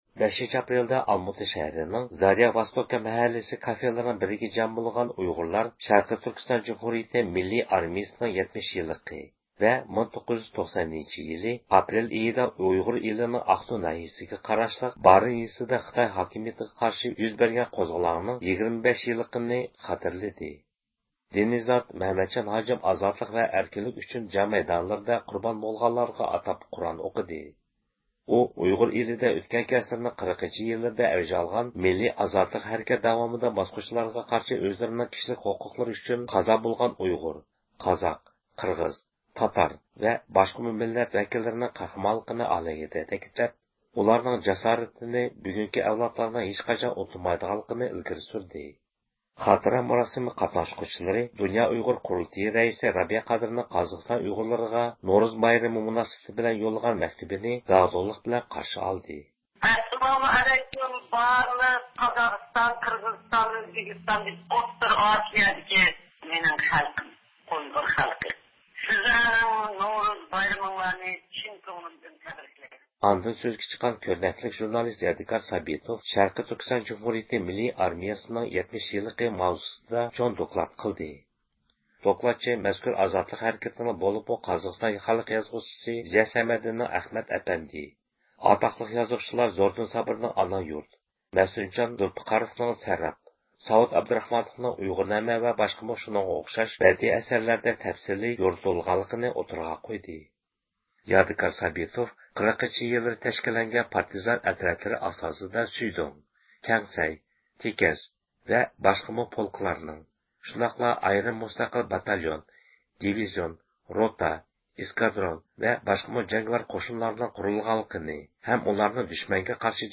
5-ئاپرېلدا ئالماتا شەھىرىنىڭ زاريا ۋوستوكا مەھەللىسى كافېلىرىنىڭ بىرىگە جەم بولغان ئۇيغۇرلار شەرقىي تۈركىستان جۇمھۇرىيىتى مىللىي ئارمىيىسىنىڭ 70 يىللىقىنى ۋە 1990-يىلى ئاپرېل ئېيىدا ئۇيغۇر ئېلىنىڭ ئاقتۇ ناھىيىسىگە قاراشلىق بارىن يېزىسىدا خىتاي ھاكىمىيىتىگە قارشى يۈز بەرگەن قوزغىلاڭنىڭ 25 يىللىقىنى خاتىرىلىدى.